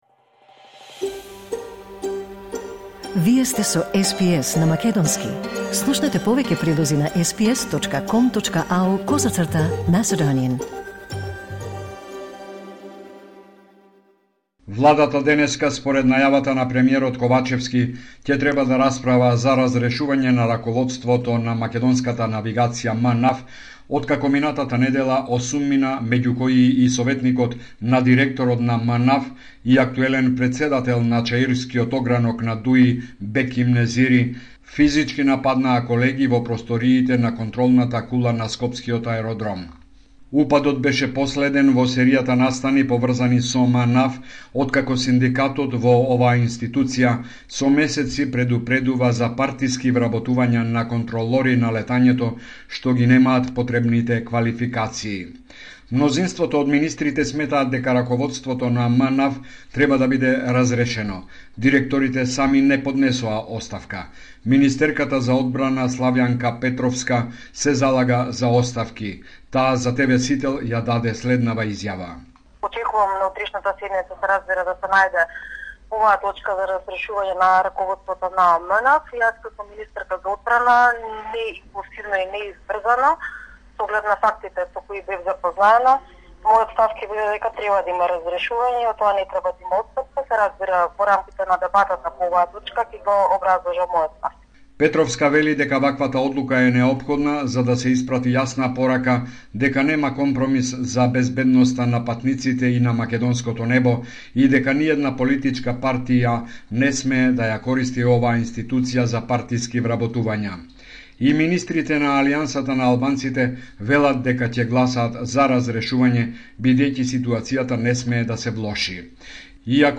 Homeland Report in Macedonian 9 January 2024